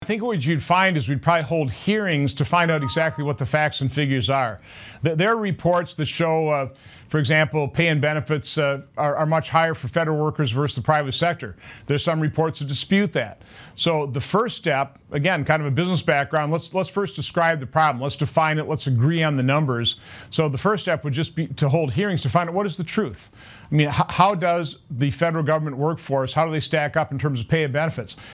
Senator Johnson gave these answers during an interview on Wednesday, Nov. 12, with WBAY-TV.